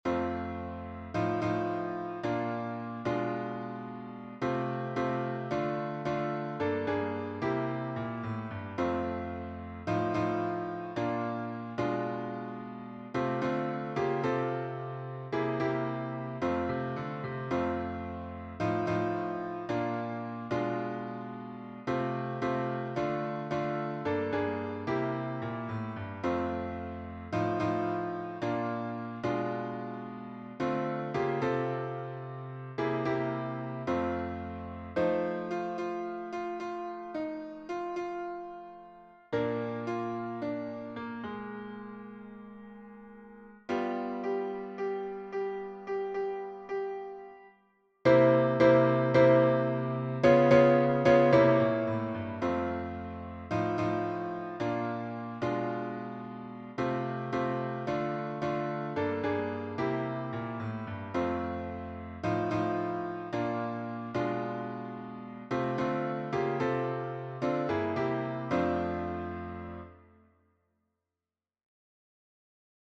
- Œuvre pour choeur à 4 voix a capella
MP3 version piano